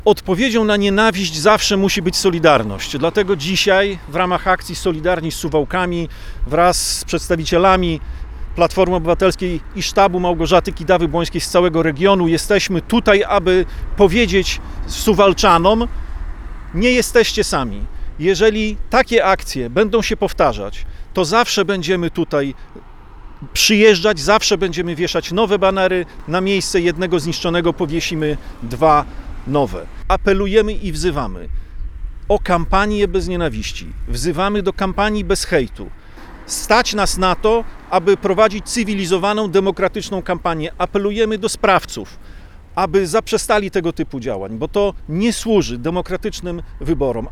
– Apelujemy o solidarność i kampanie wyborczą bez hejtu – mówił na konferencji Robert Tyszkiewicz, poseł Platformy Obywatelskiej.